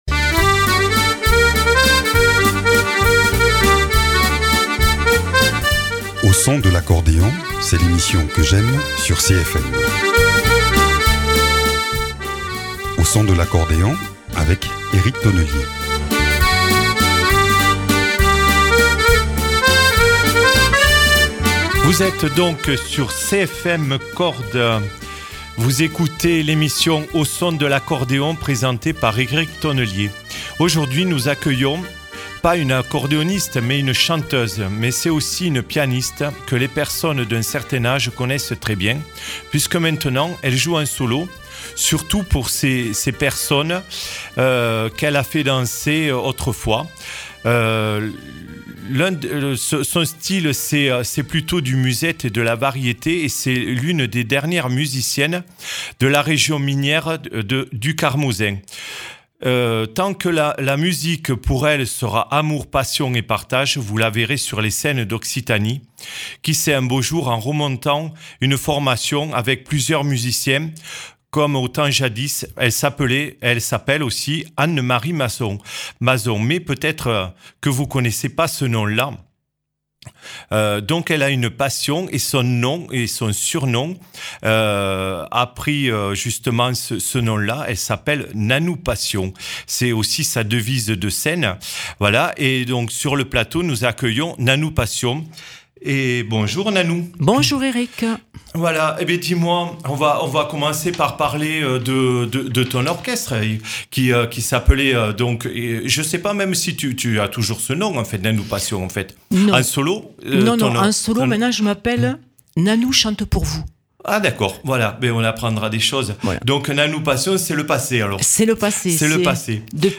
pianiste et accordéoniste.